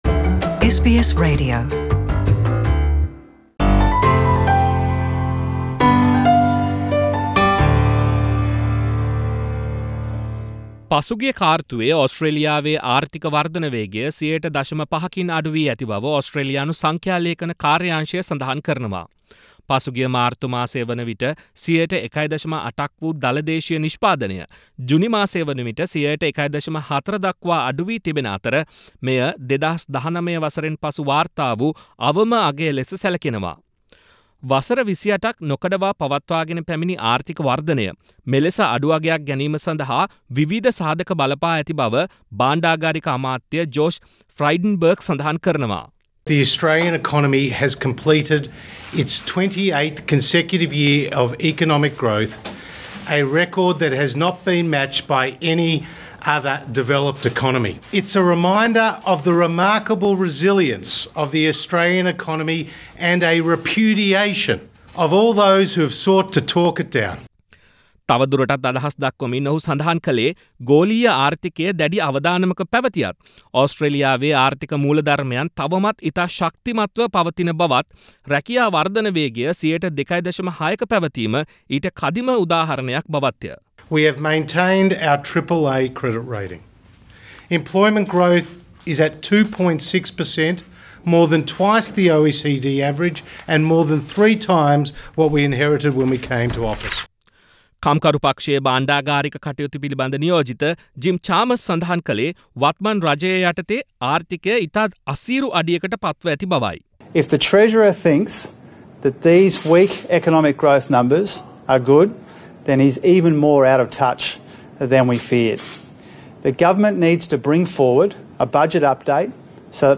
පසුගිය කාර්තුවේ Australia වේ ආර්ථික වර්ධන වේගය 0.5% න් අඩු වී ඇතිබව නවතම දත්ත මගින් අනාවරණය වේ. මේ ඒ පිලිබඳ ගෙනෙන විශේෂ වාර්තාවක්.